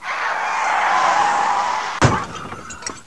Effet sonore - wav Accident de voiture
Bruit de freinage de voiture, avec un accident (bruit de collision de voiture) et le bruit de bris de verre (phares).